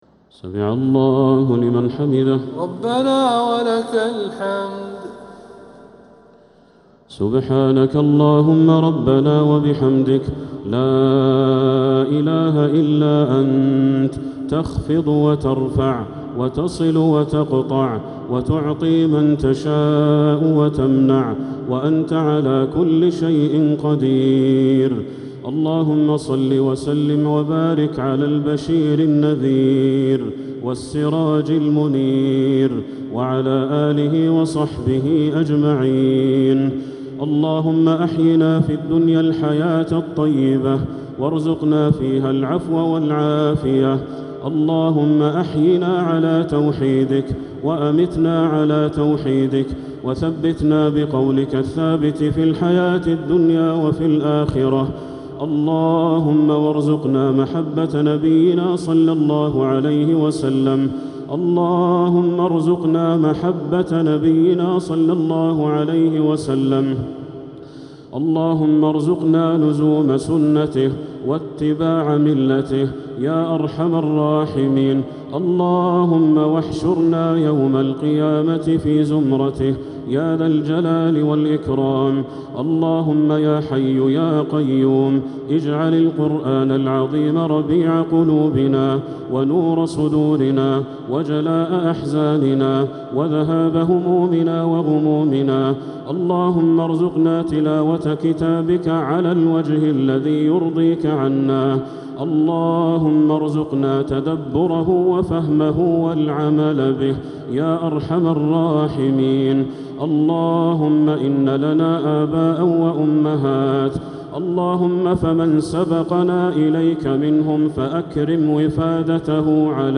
دعاء القنوت ليلة 11 رمضان 1447هـ | Dua 11th night Ramadan 1447H > تراويح الحرم المكي عام 1447 🕋 > التراويح - تلاوات الحرمين